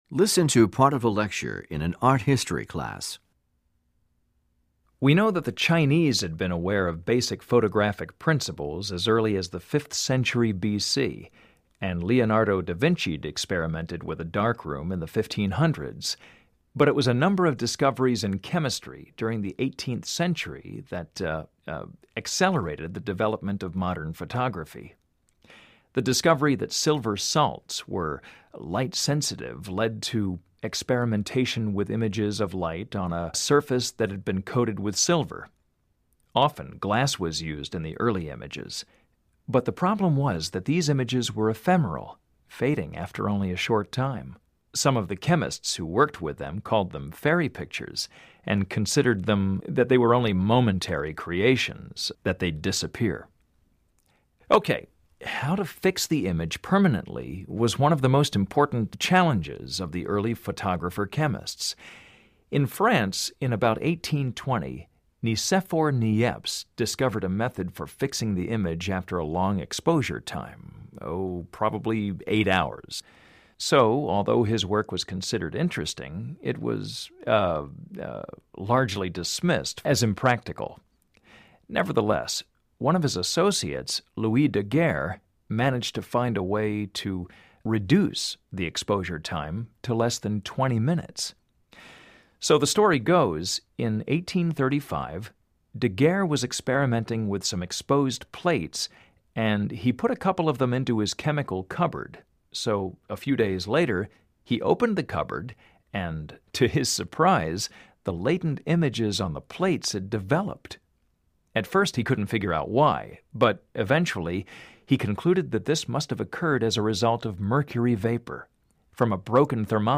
Directions: This section measures your ability to understand conversations and lectures in English.